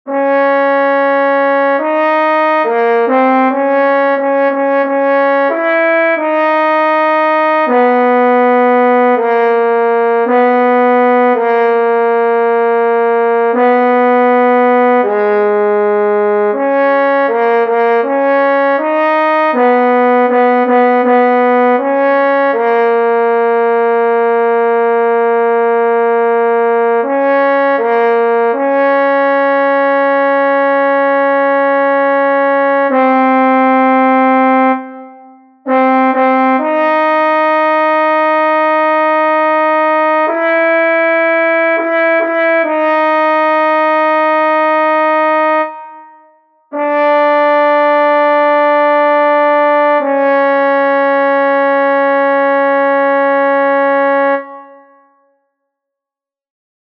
Key written in: F# Major
Type: Barbershop